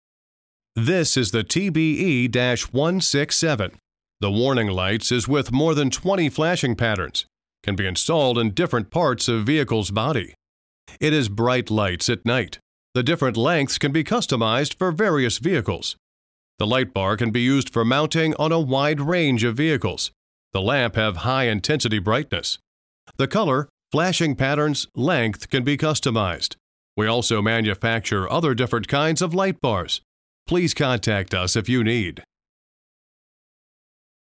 LED Traffic Advisor Directional Light sound effects free download